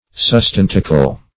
sustentacle - definition of sustentacle - synonyms, pronunciation, spelling from Free Dictionary
Search Result for " sustentacle" : The Collaborative International Dictionary of English v.0.48: Sustentacle \Sus*ten"ta*cle\, n. [L. sustentaculum.